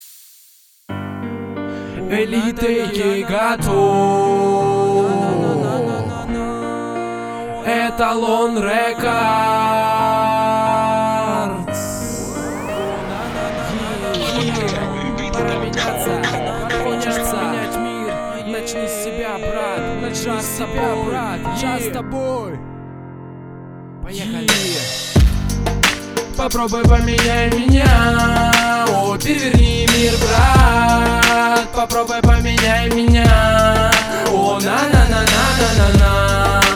Главная » рингтоны на телефон » Рэп, Хип-Хоп, R'n'B